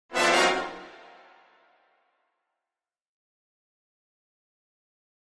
Brass Section, Short Reminder, Type 3 - Double,Semitone